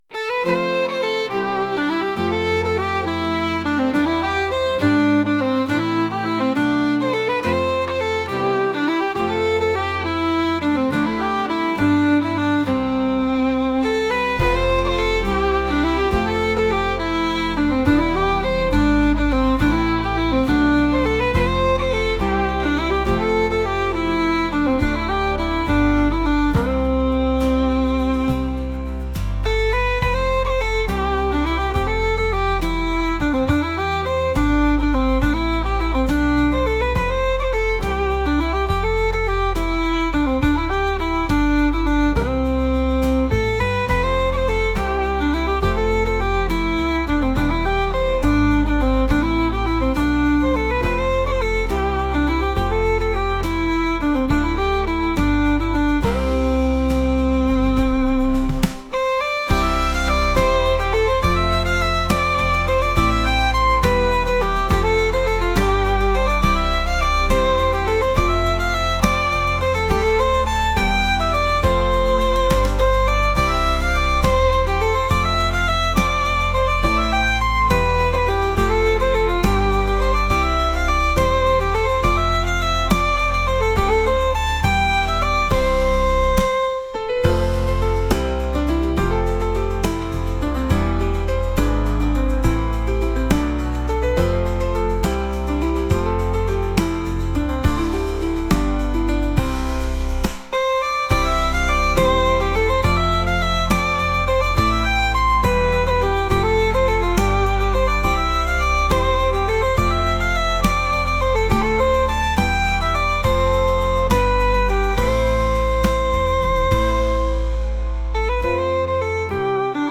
山の新緑に目を奪われるようなケルト音楽です。